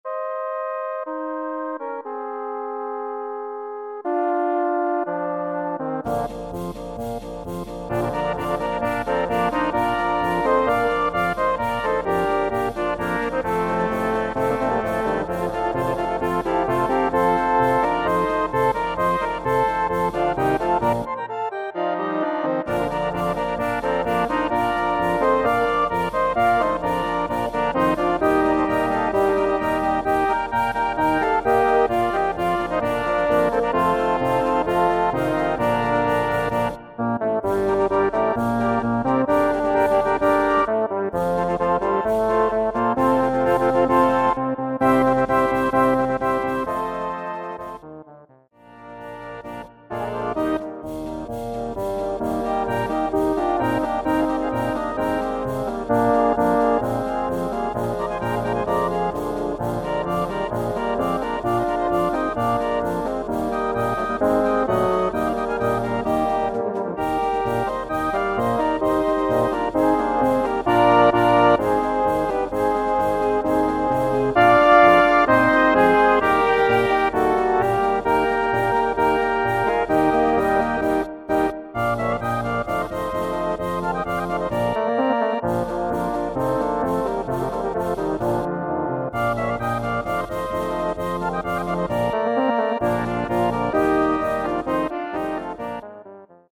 Category Concert/wind/brass band
Subcategory Polka
Instrumentation Ha (concert/wind band)
Additional info/contents Böhmische Polka